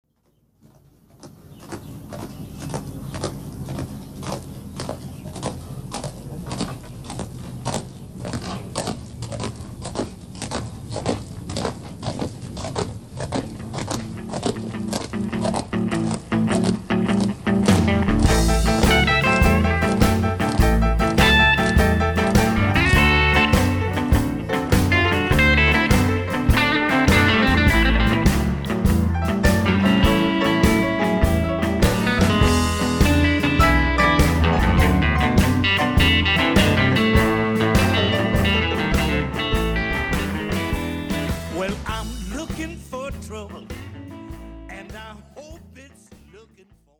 Se il ritmo delle terzine viene accentato sulla terza nota e la seconda non viene eseguita si trasforma in qualcosa di diverso: è lo shuffle ritmo base del blues.
Chicago shuffle (mix con lo scalpiccio di un cavallo)
xHorseshuffle.mp3